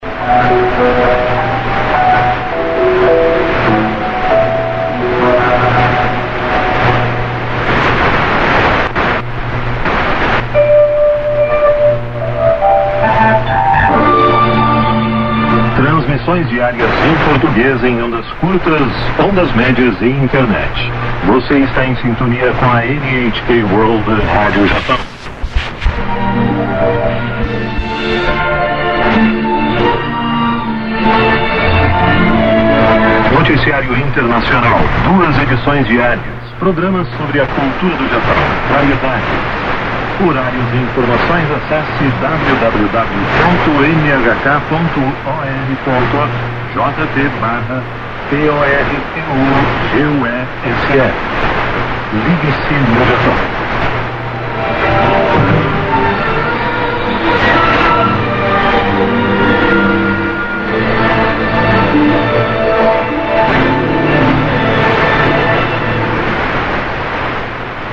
NHK Rádio Japão Freqüência 17.540